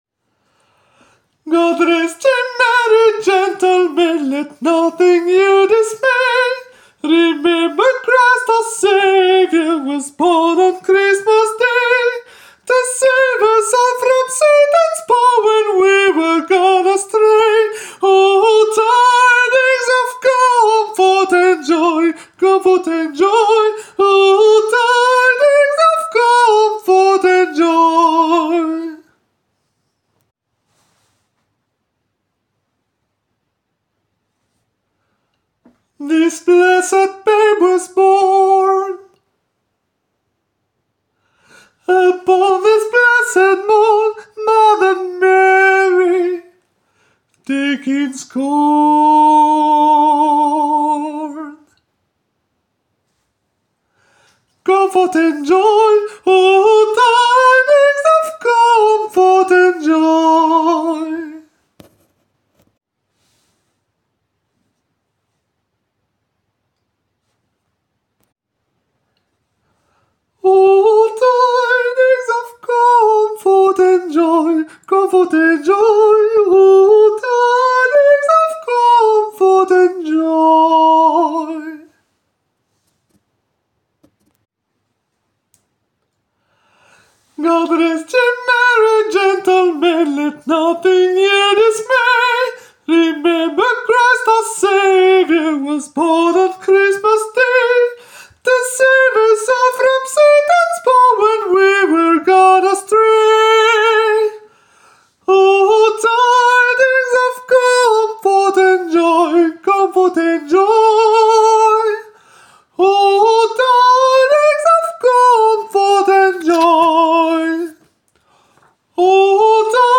soprane
GOD-REST-YE-MERRY-GENTLEMEN-soprano.aac